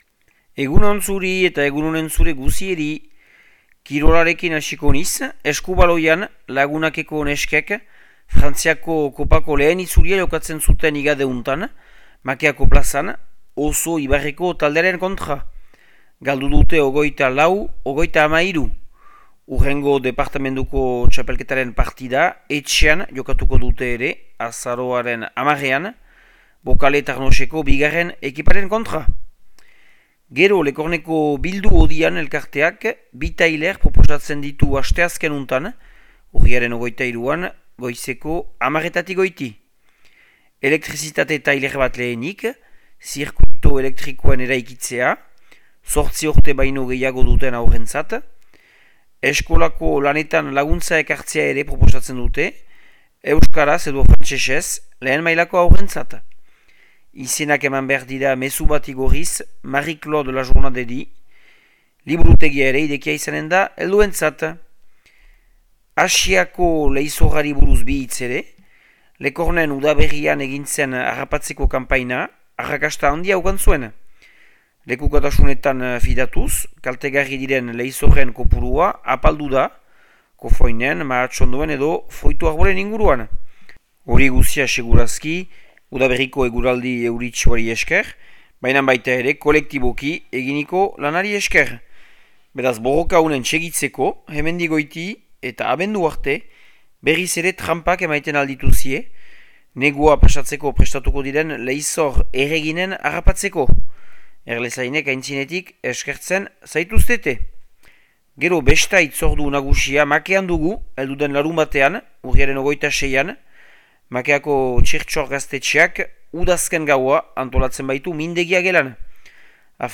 Urriaren 21eko Makea eta Lekorneko berriak